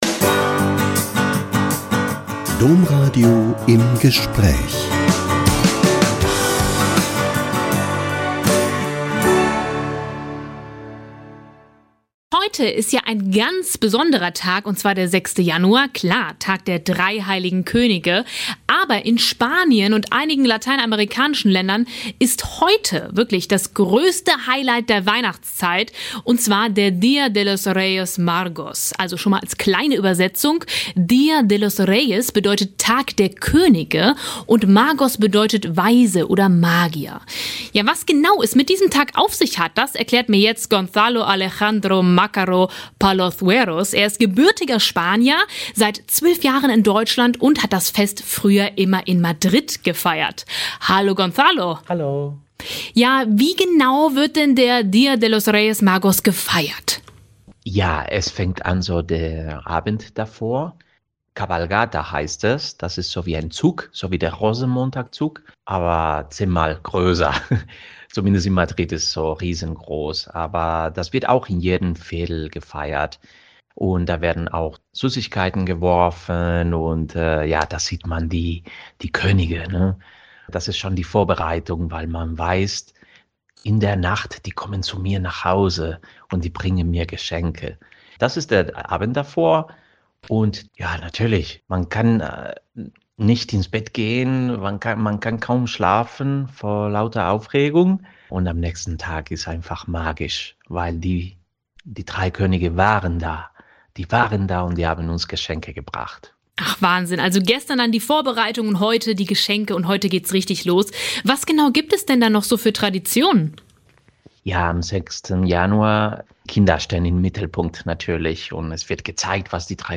Ein Spanier erklärt, wie der Día de los Reyes Magos gefeiert wird